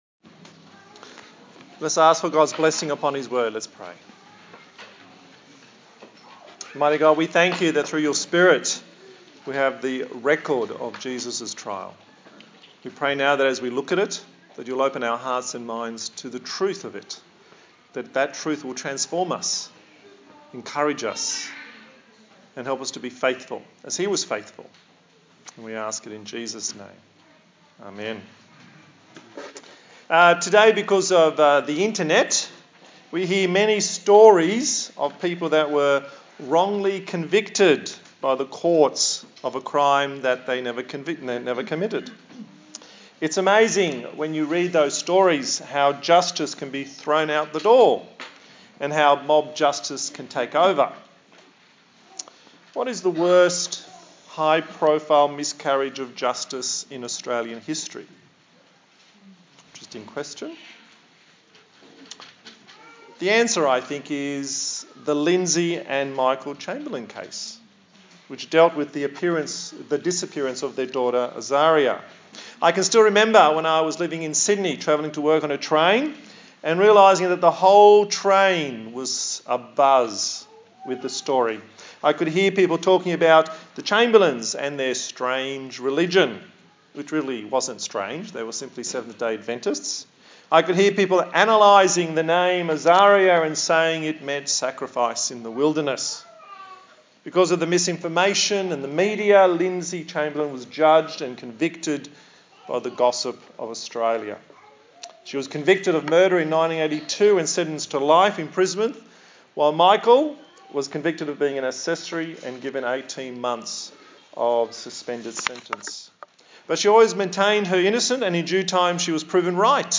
A sermon
Service Type: Sunday Morning